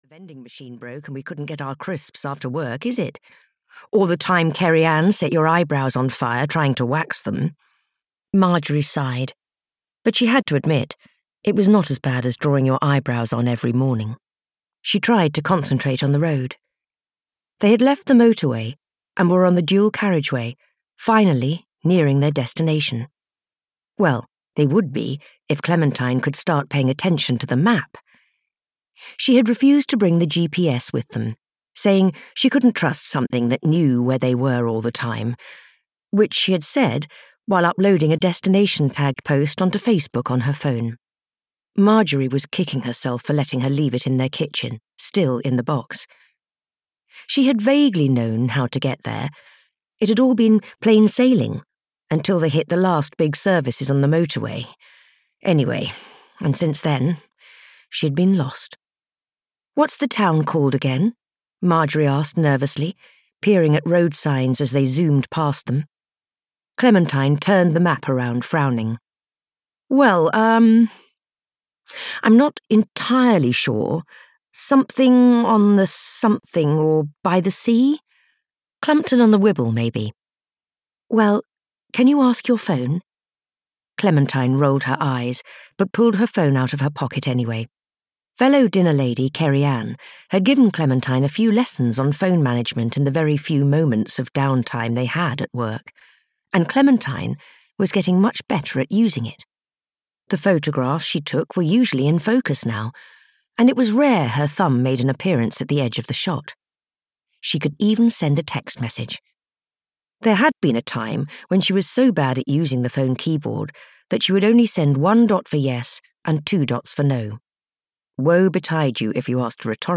A Terrible Village Poisoning (EN) audiokniha
Ukázka z knihy